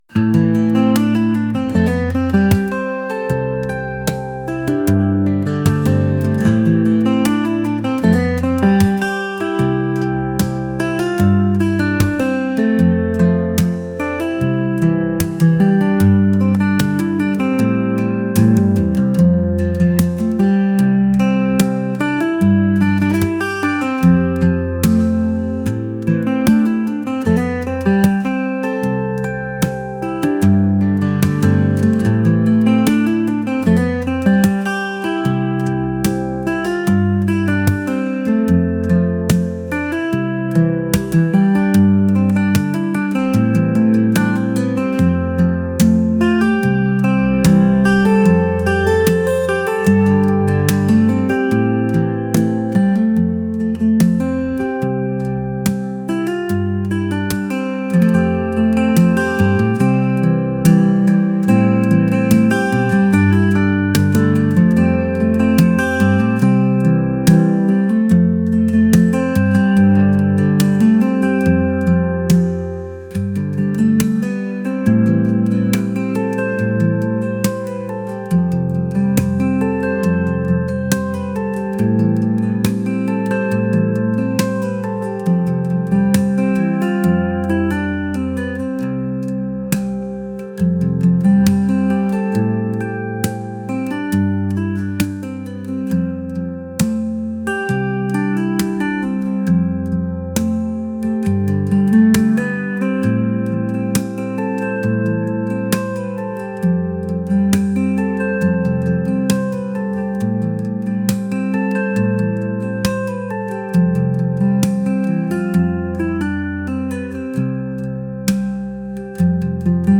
acoustic | folk